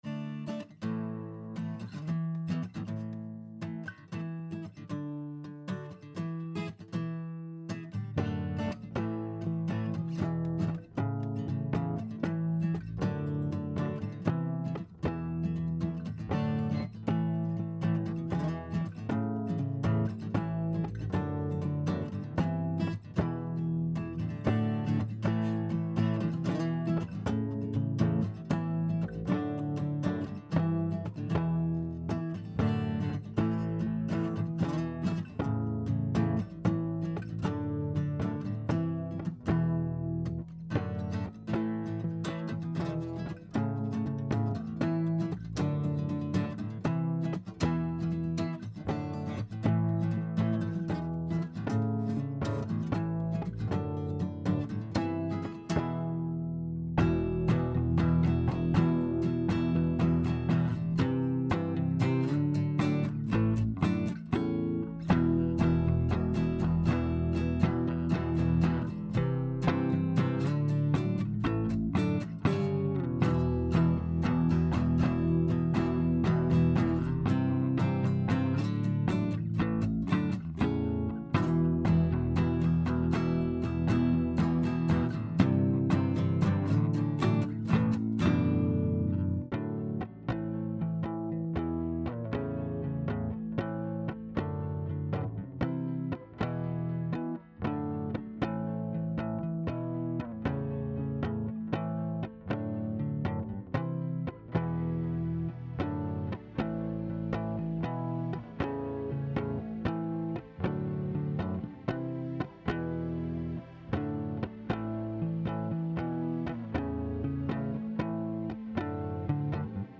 Basslastiger Song sucht Feedback und/oder weitere Instrumente
Der Song ist absolut null gemixt, manche Übergänge sind schrecklich und generell fehlt noch einiges, damit die Sache rund wird.